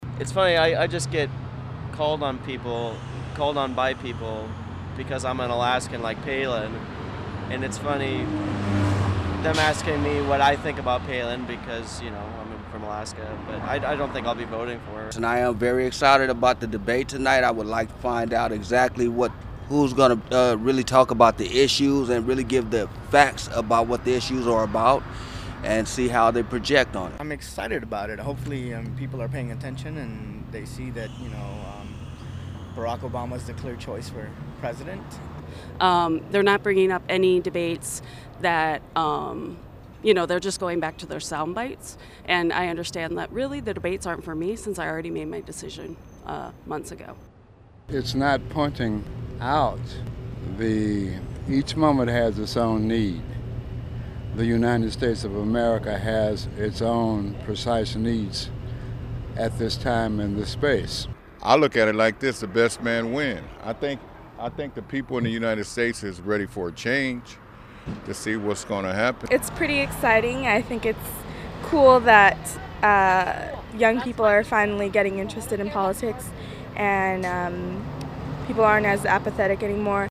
But what about Angelenos who want to voice their concerns? We went to the Figueroa Corridor to ask locals what's on their minds when it comes to the election.
debatevoxpops.mp3